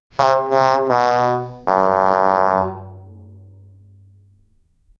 sad trombone, muted trumpet, sassy fail cartoon womp womp sound effect followed closely by a rimshot joke drum sound effect for comedy cartoon, ba dum tss, live drumset, punchline, realistic
sad-trombone-muted-trumpe-xaub3dyc.wav